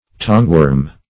tongueworm - definition of tongueworm - synonyms, pronunciation, spelling from Free Dictionary Search Result for " tongueworm" : The Collaborative International Dictionary of English v.0.48: Tongueworm \Tongue"worm`\, n. (Zool.)